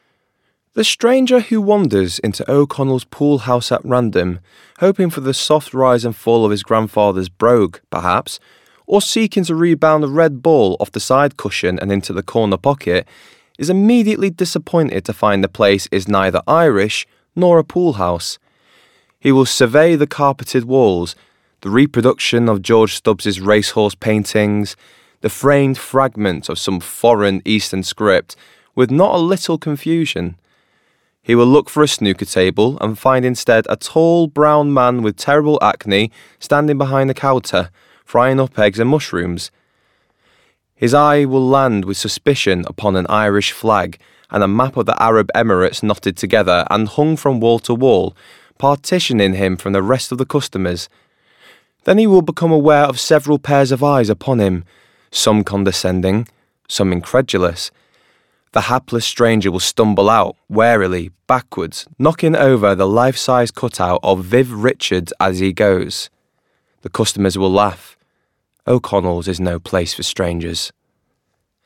20s – 30s. Male. Manchester.
Audiobook